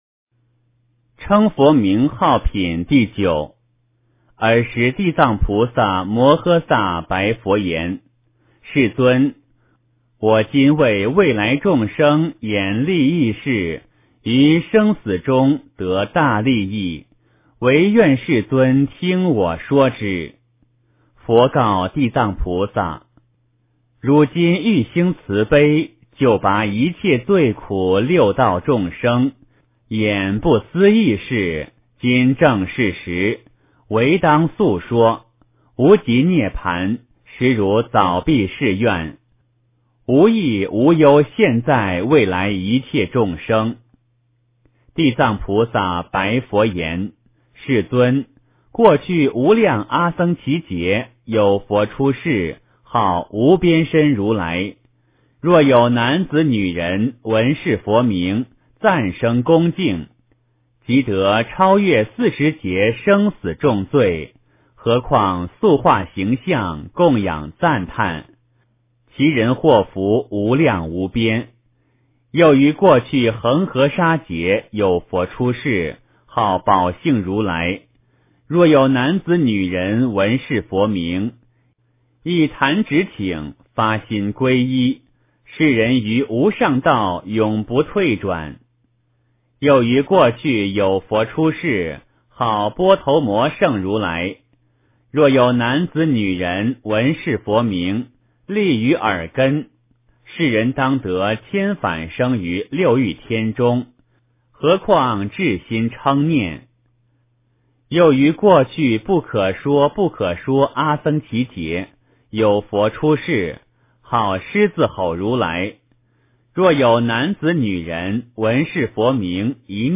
地藏经-称佛名号品第九 诵经 地藏经-称佛名号品第九--佛经 点我： 标签: 佛音 诵经 佛教音乐 返回列表 上一篇： 佛说阿弥陀经上 下一篇： 地藏经-较量布施功德缘品第十 相关文章 梅花三弄--中央民族乐团 梅花三弄--中央民族乐团...